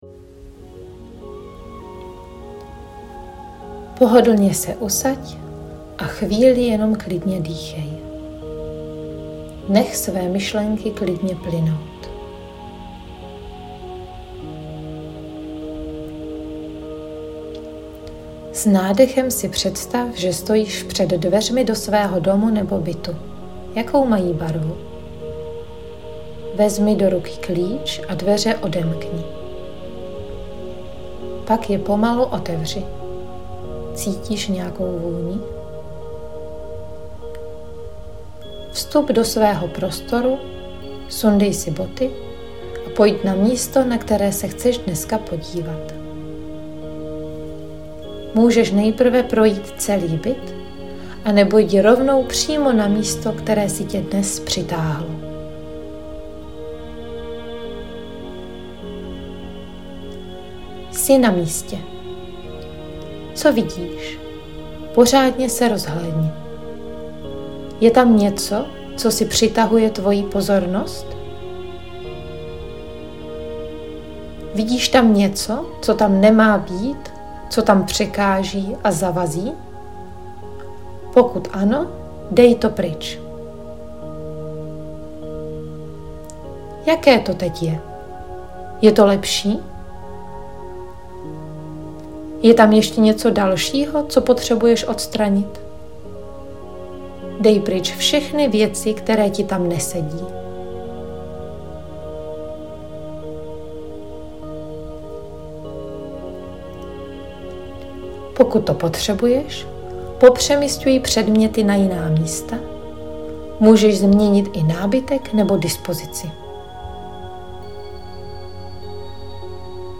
Meditaci pro změnu prostoru
Zmena prostoru meditace.mp3